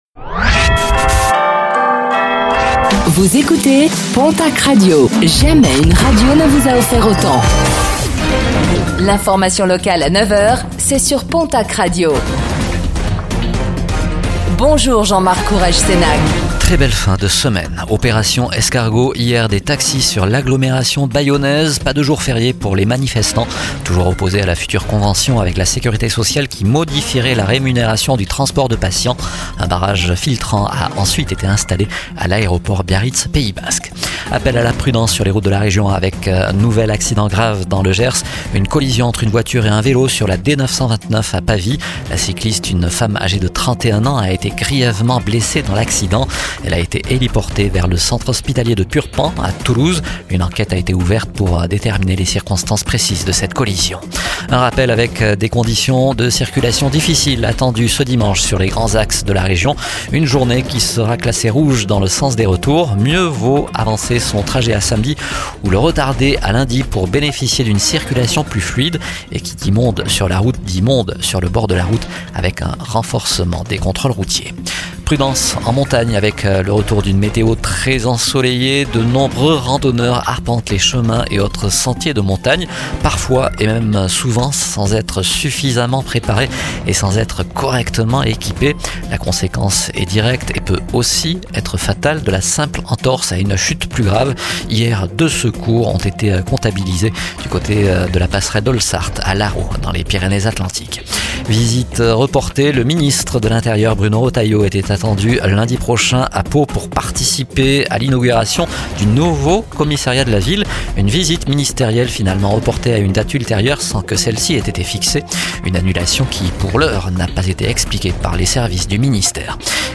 Réécoutez le flash d'information locale de ce vendredi 30 mai 2025